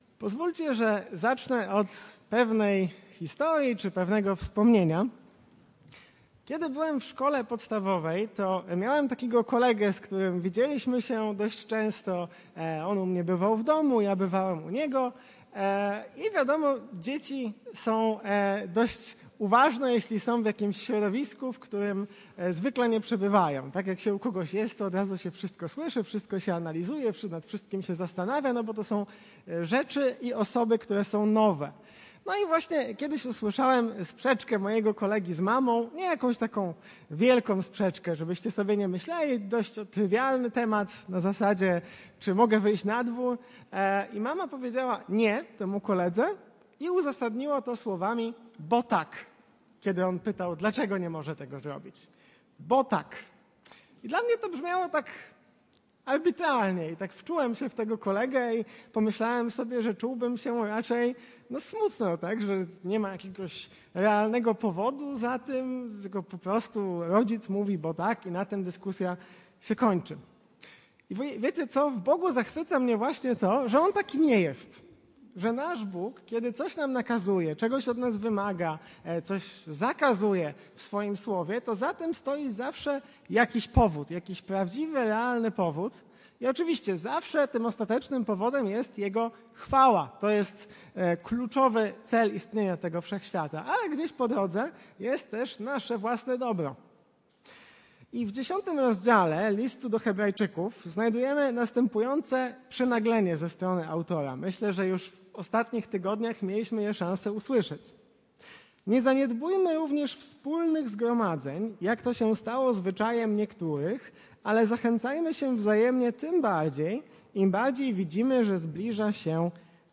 Kazanie
wygłoszone na nabożeństwie w niedzielę 27 kwietnia 2025 r.